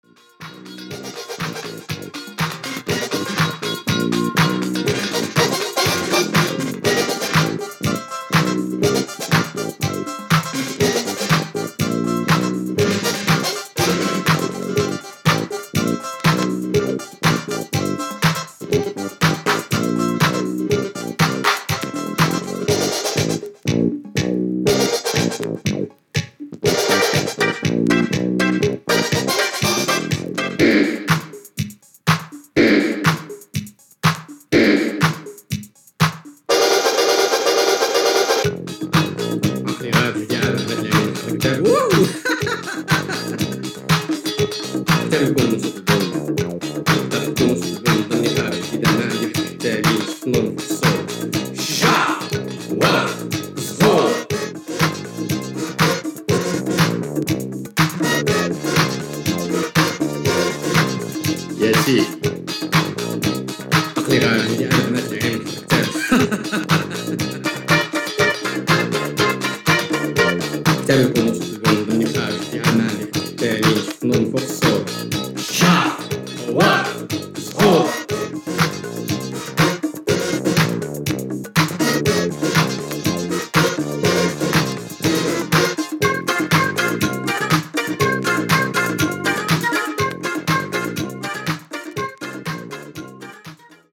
Cosmic